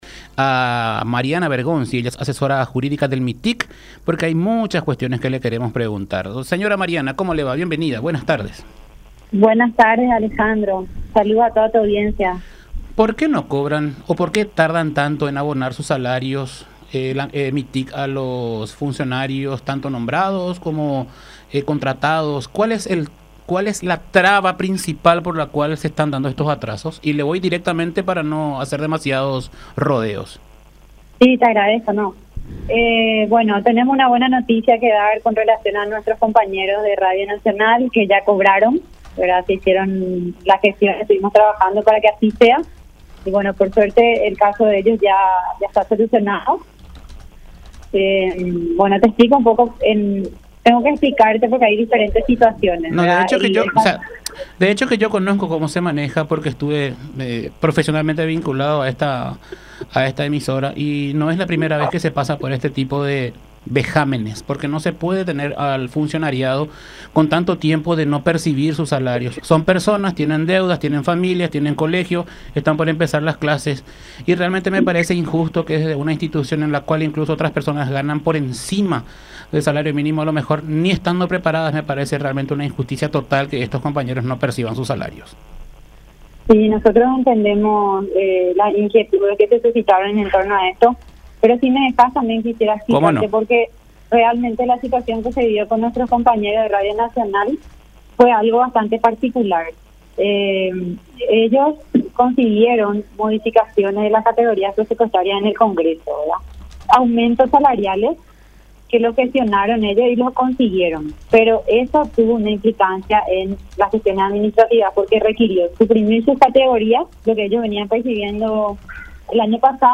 en dialogo con La Unión AM R800